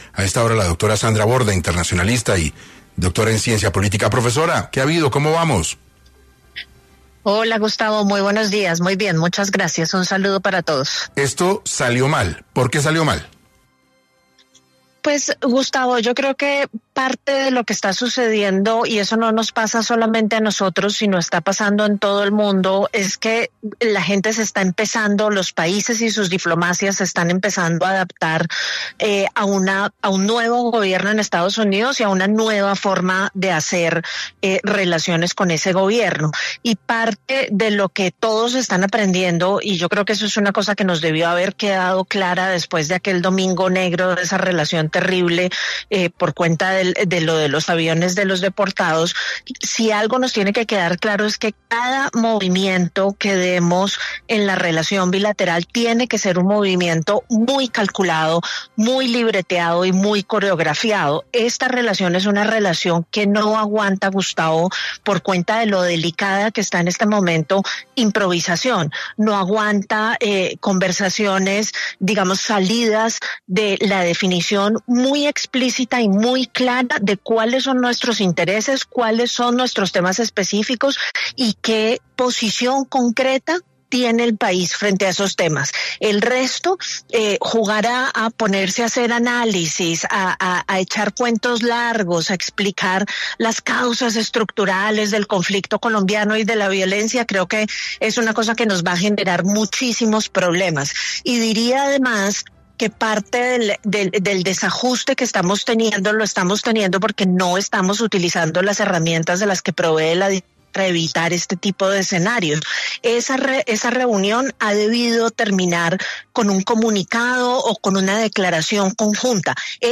En entrevista par 6AM